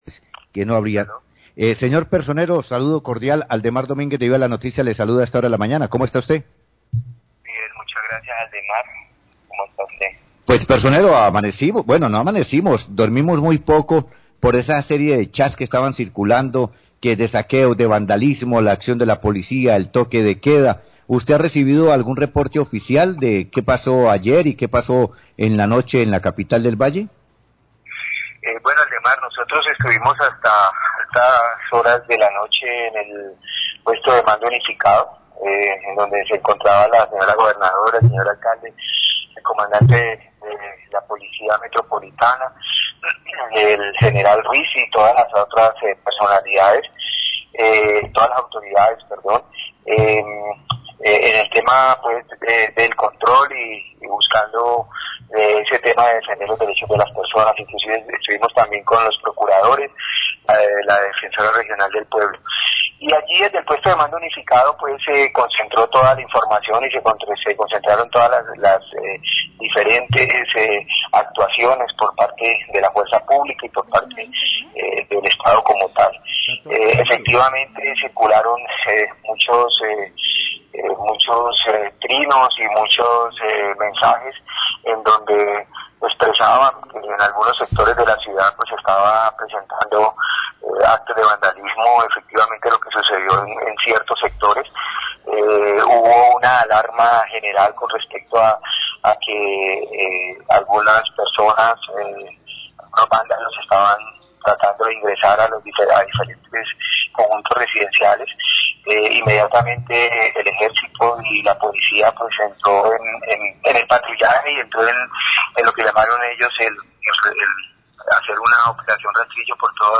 Radio
Habla el personero de Cali sobre lo que sucedio anoche y la información que recibía en el PMU.